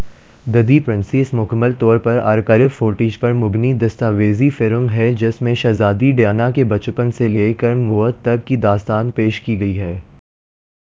Spoofed_TTS/Speaker_14/273.wav · CSALT/deepfake_detection_dataset_urdu at main